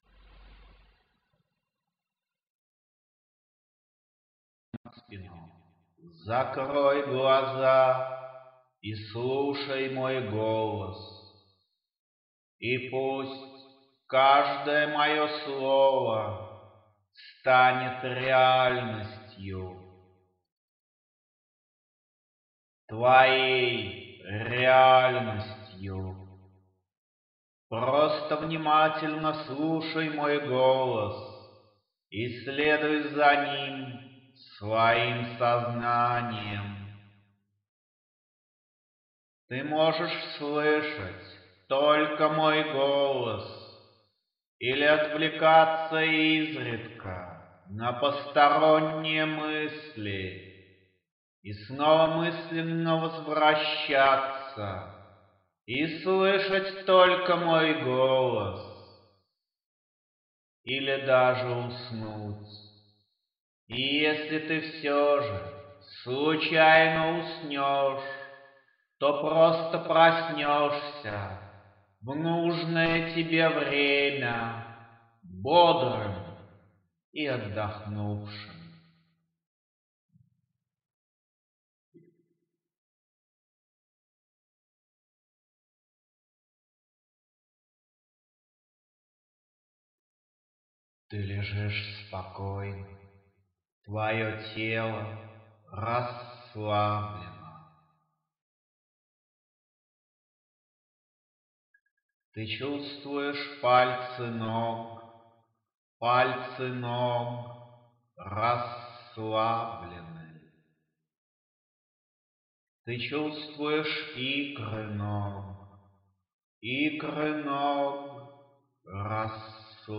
Музыка для медитации